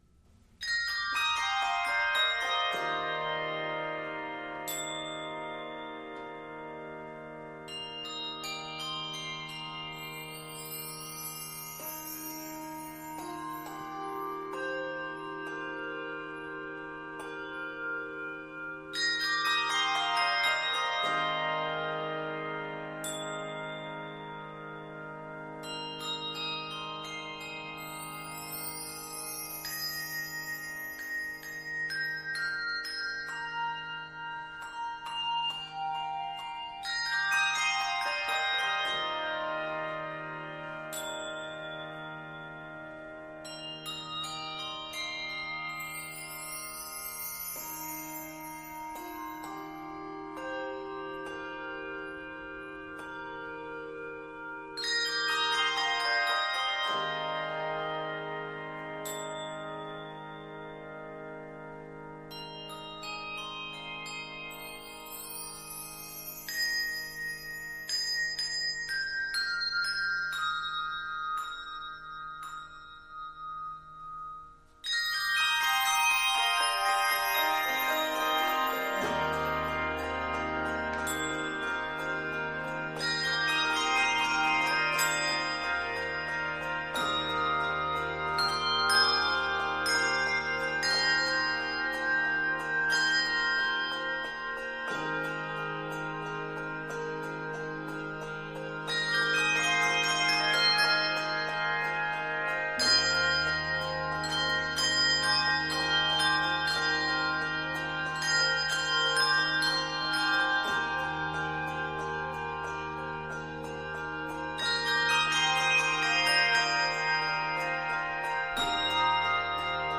handbell music
Key of Eb major. 114 measures.